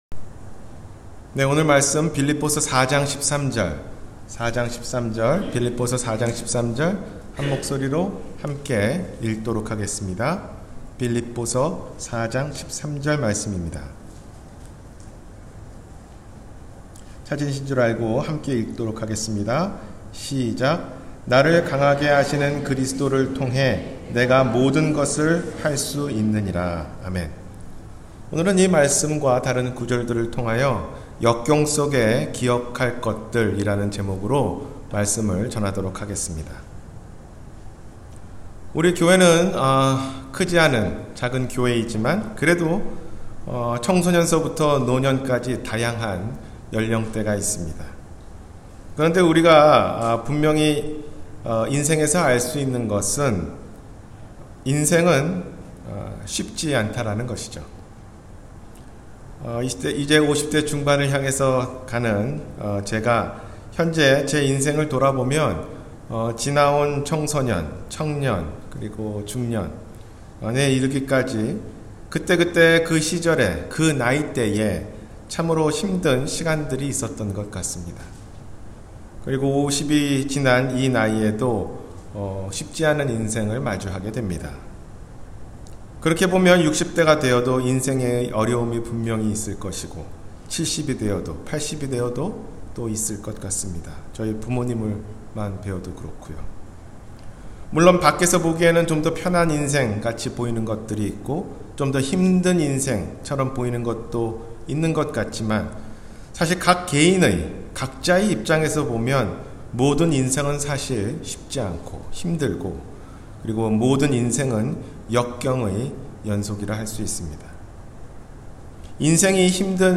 역경속에서 기억할 것 – 주일설교 – 갈보리사랑침례교회